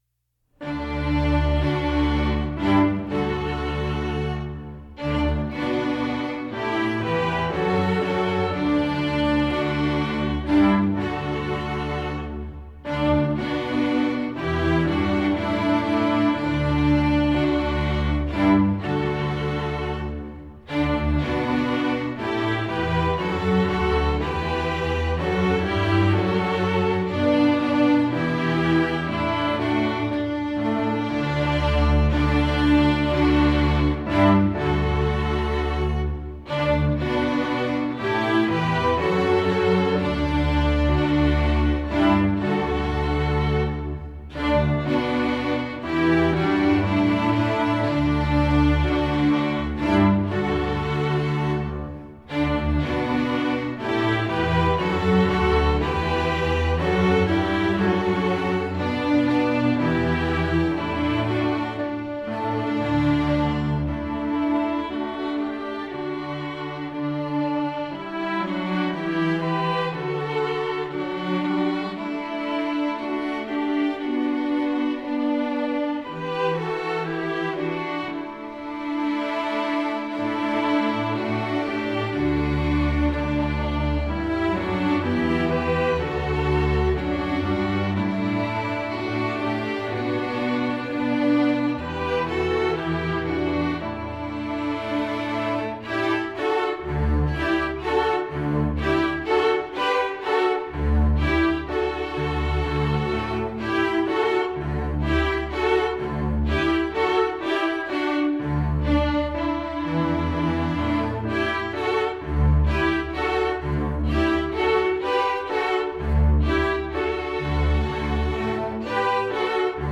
Voicing: String Orchestra W